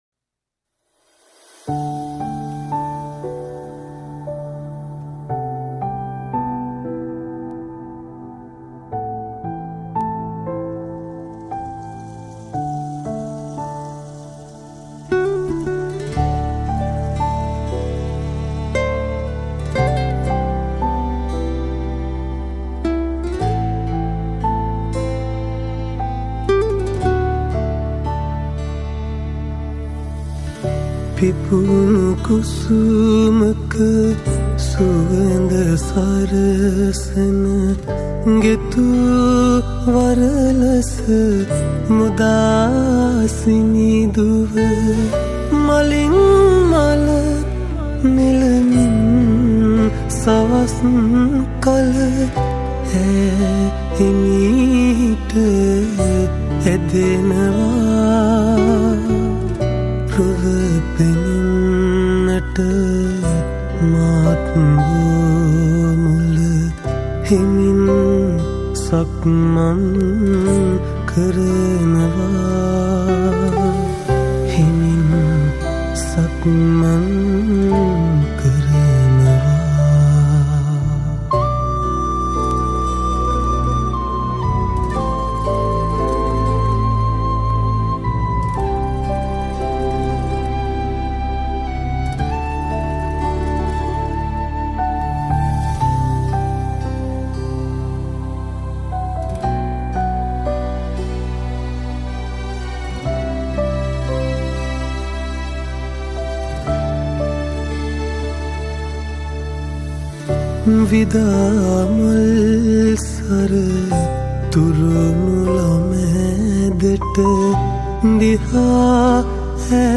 Guitars